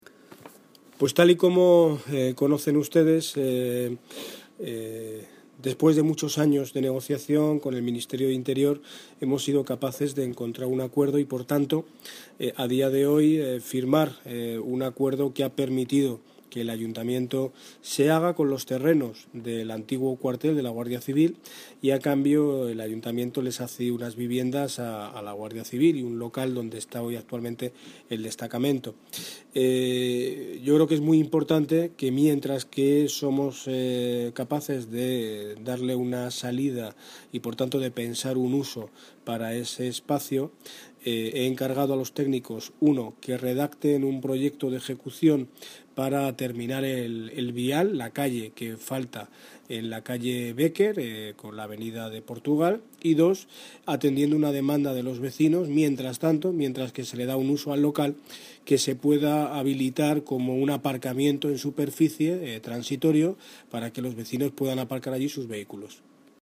Audio - Daniel Ortiz (Alcalde de Móstoles) Sobre antiguo cuartel